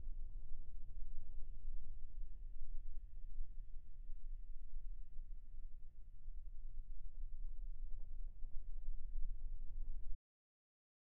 This is caused by wind interacting with the feedforward microphones outside the ear cups or ear canal.
Sony WH-1000XM6 Wind Noise
xm6-wind-noise.wav